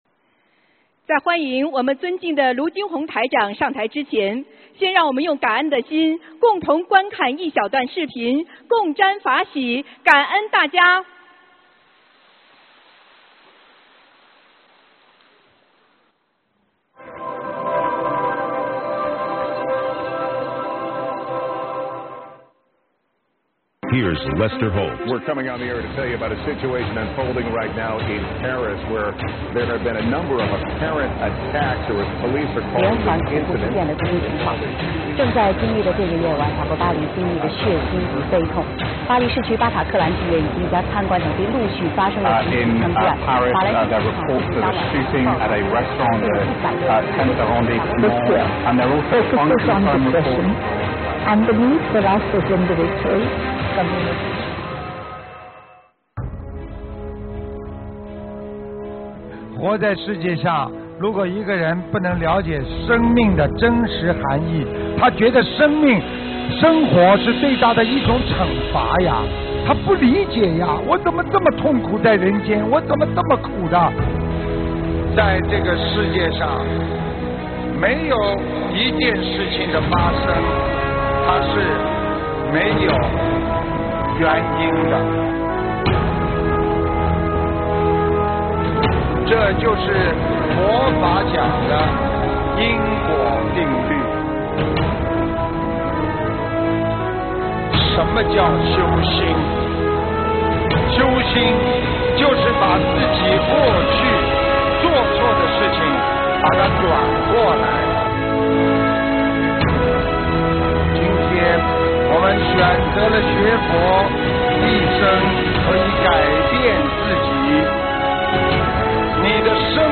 【2017马来西亚·吉隆坡】8月25日 佛友见面会 文字+音频 - 2017法会合集 (全) 慈悲妙音